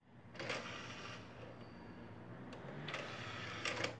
上链曲柄
标签： 机械 电机 机械 机器人 机器 机器人 呼呼 嗡嗡 自旋 曲柄 旋转 火炬 发电机 卷取 手电筒
声道立体声